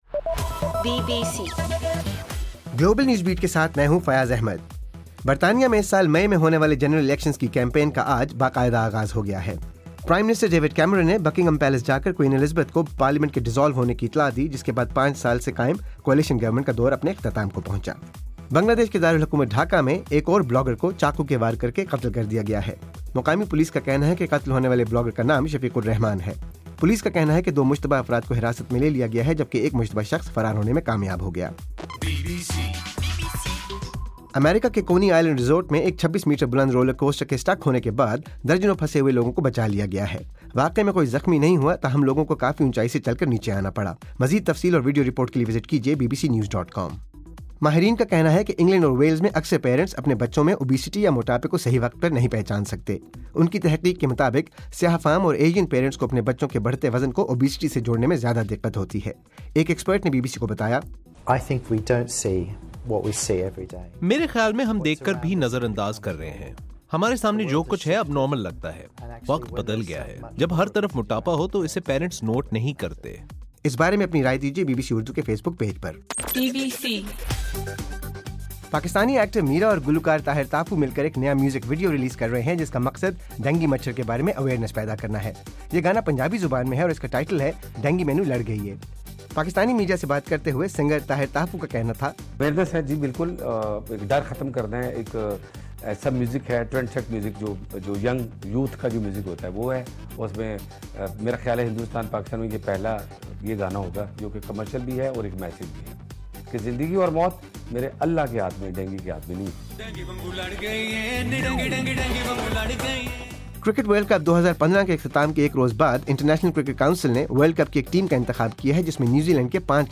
مارچ 30: رات 8 بجے کا گلوبل نیوز بیٹ بُلیٹن